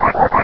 sovereignx/sound/direct_sound_samples/cries/numel.aif at master